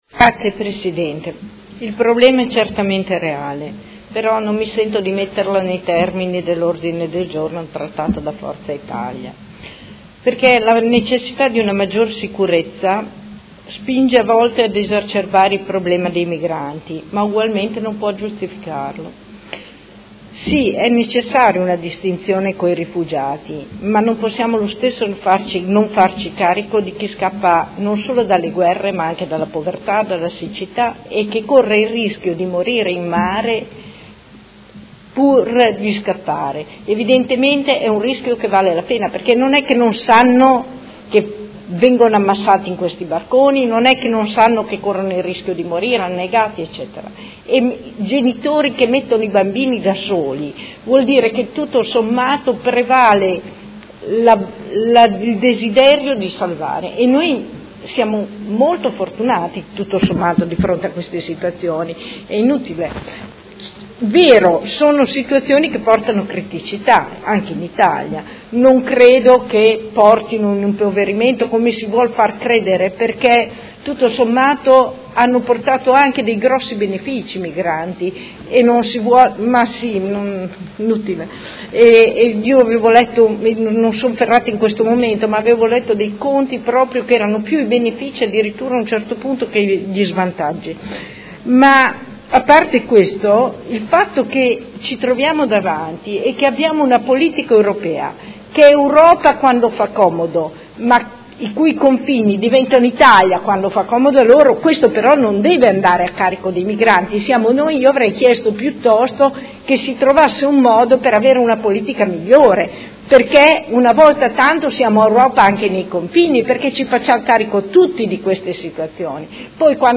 Seduta del 16/02/2017. Dibattito su Mozione presentata dal Gruppo Forza Italia avente per oggetto: Le nostre proposte per l’emergenza immigrazione e per la sicurezza